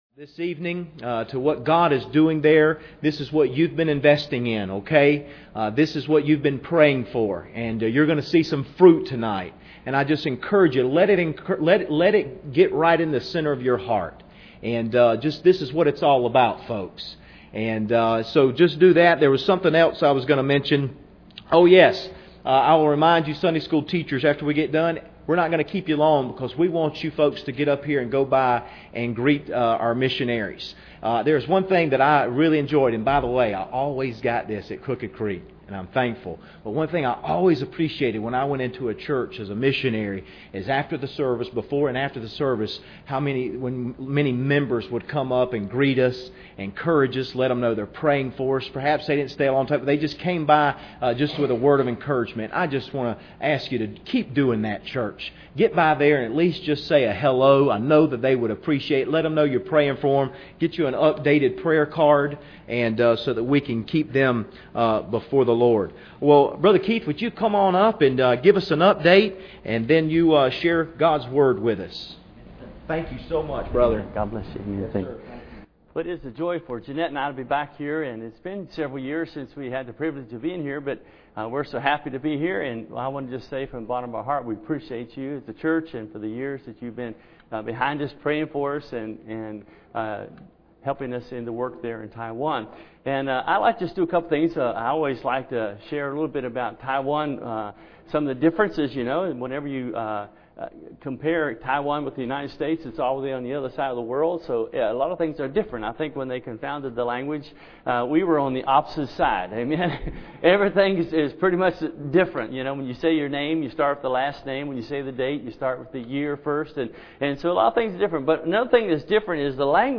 Acts 8:26-35 Service Type: Wednesday Evening Bible Text